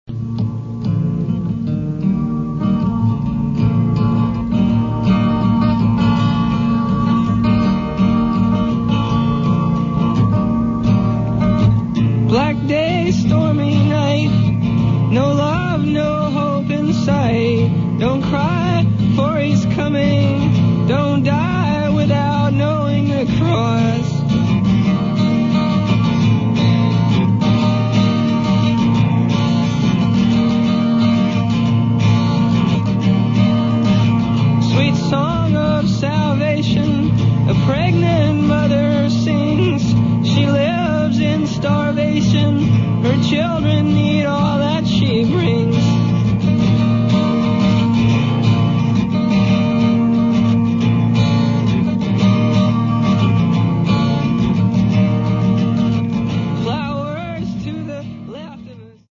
Sessions radios & lives inédits